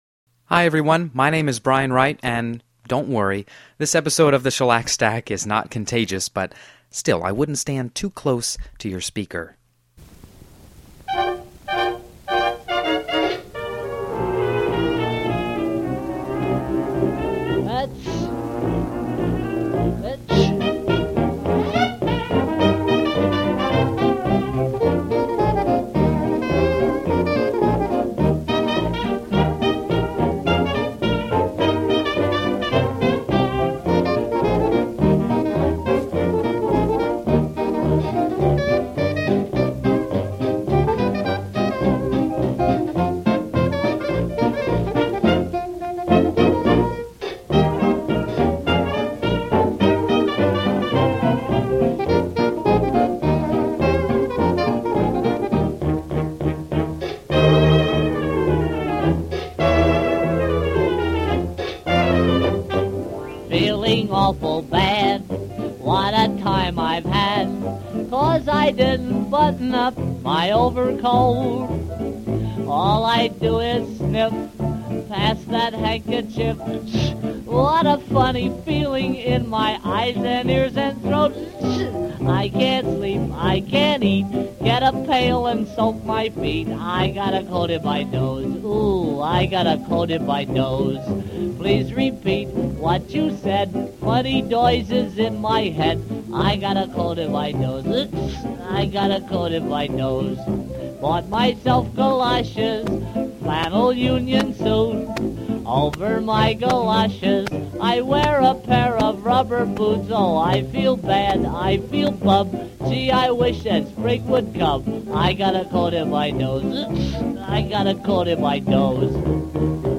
Alpine yodeling
from ragtime to doo-wop
this eclectic batch of 78s will keep you on your toes!